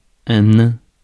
Ääntäminen
IPA : /dwɔː(ɹ)f/ IPA : /ˈdwɔɹf/ IPA : /ˈdwɔːf/